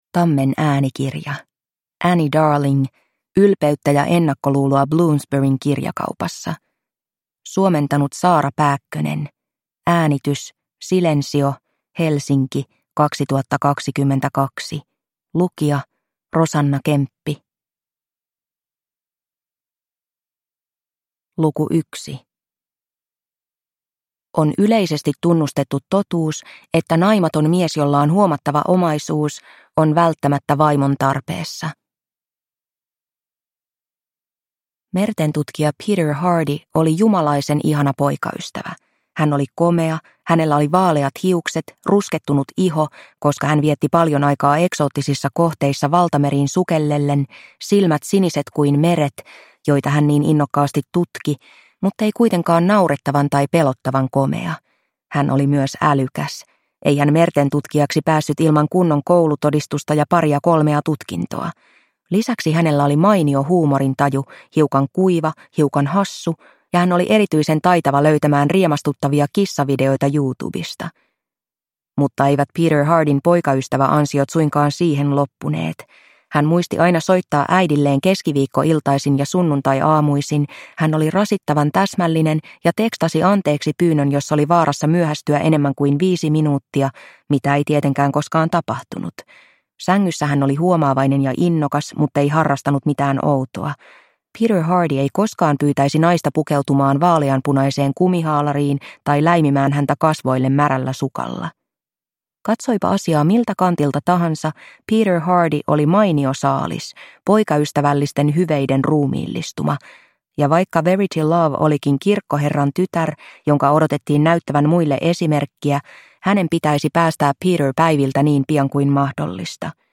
Ylpeyttä ja ennakkoluuloa Bloomsburyn kirjakaupassa – Ljudbok